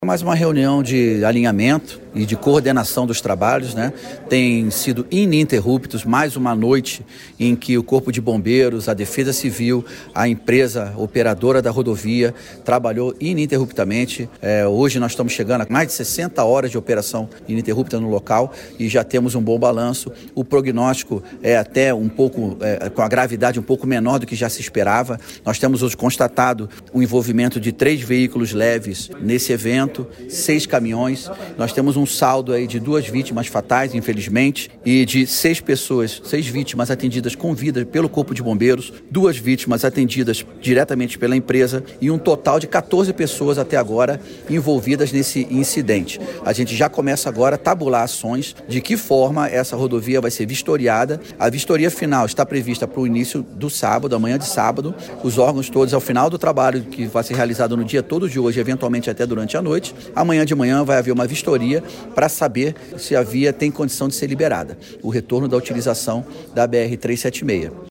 Sonora do secretário de Segurança Pública do Paraná, Wagner Mesquita, sobre o quarto dia de buscas na BR-376